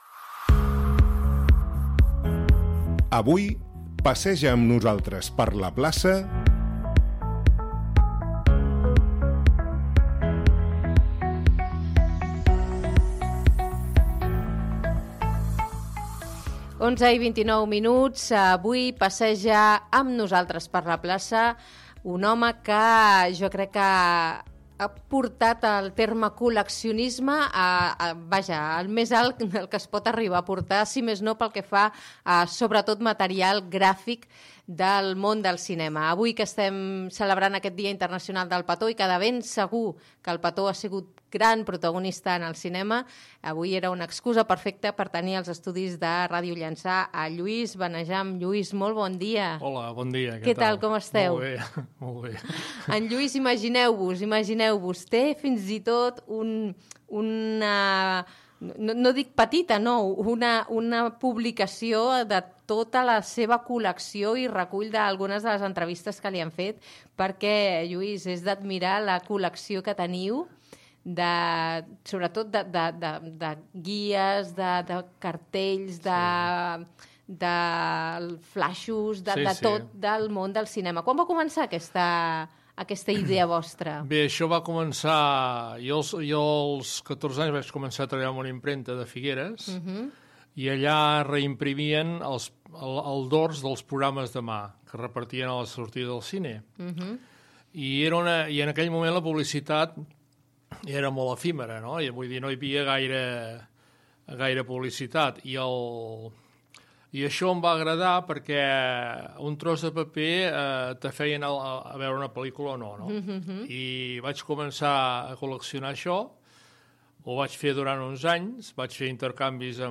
radio_llansa.mp3.mp3